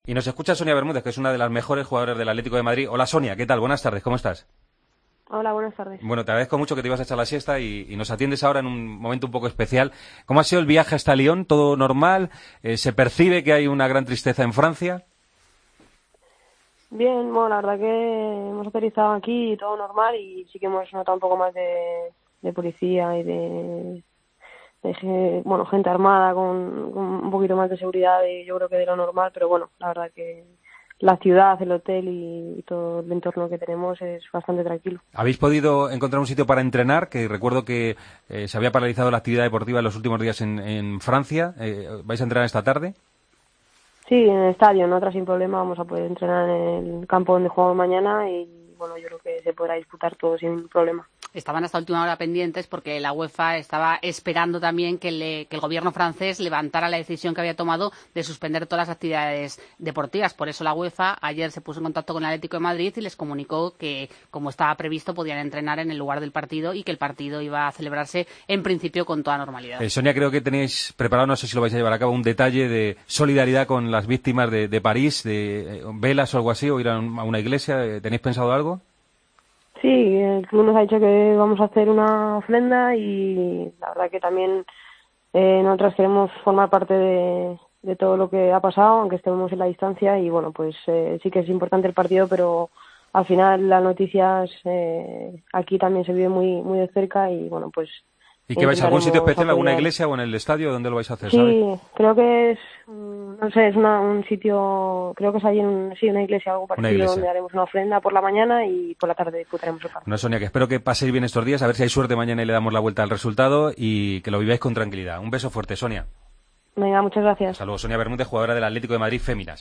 La jugadora del Atlético de Madrid femenino habló para Deportes COPE del enfrentamiento contra el Olympique de Lyon: "Si que hemos visto más seguridad de lo normal. Todo el entorno es bastante tranquilo. Vamos a poder entrenar en el estadio donde se va a jugar el encuentro, sin problema. Vamos una ofrenda por las víctimas del atentado".